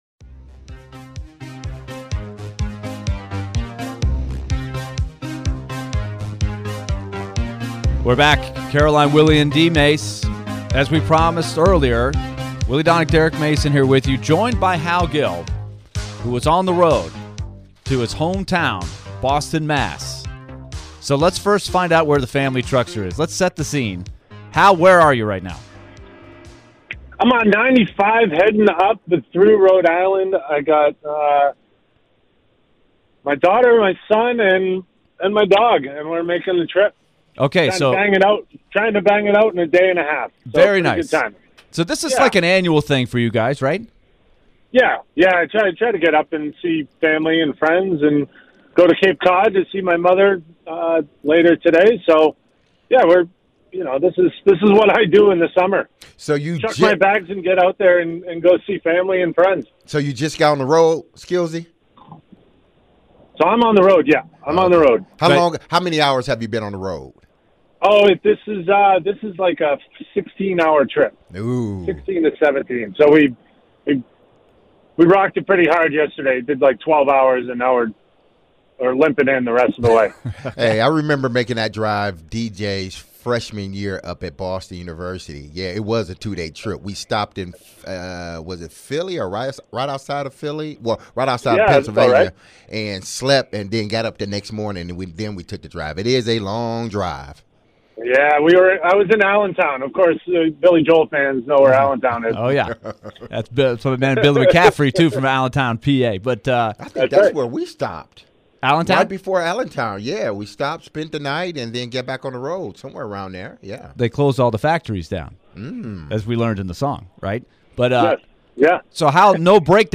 Hal Gill Interview (6-6-2023)
Predators Analyst and former player Hal Gill joined to discuss Andrew Brunette's coaching style, the importance of good goalie play and depth in the NHL and more.